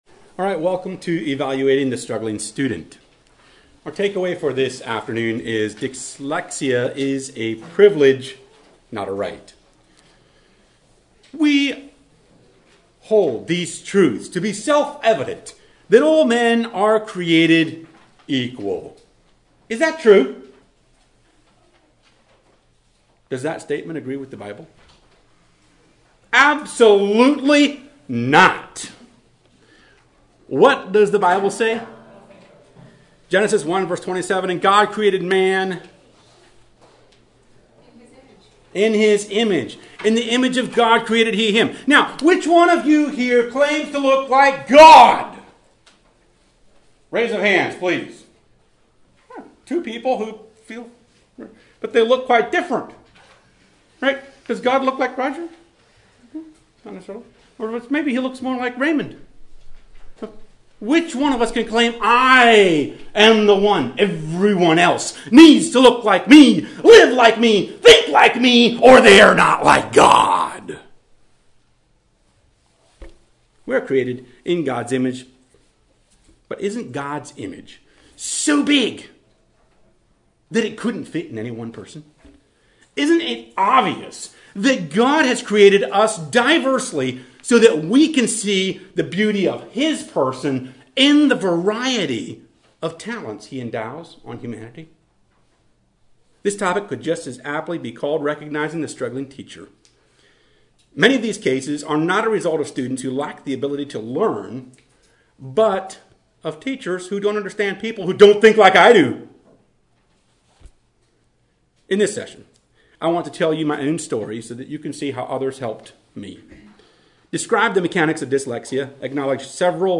This workshop will focus on recognizing signs of dyslexia as well as a few other learning difficulties such as fetal alcohol syndrome.